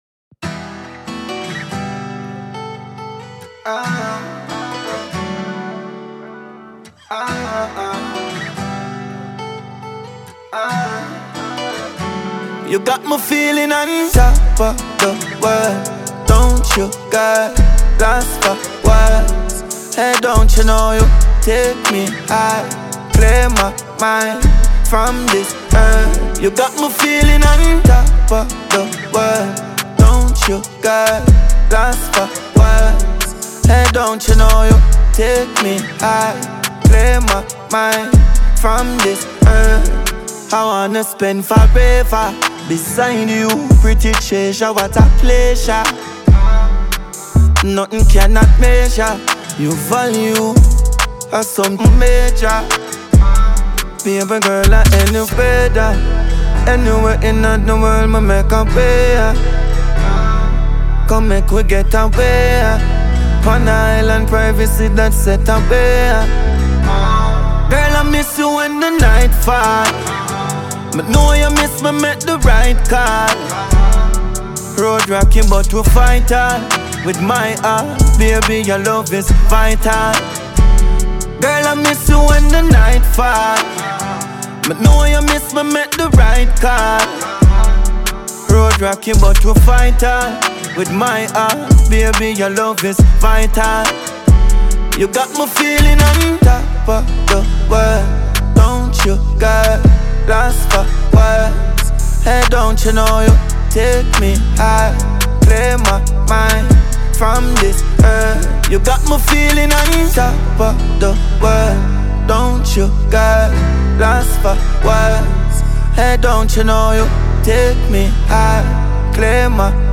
• Genre: Dancehall